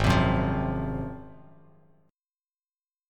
G#13 chord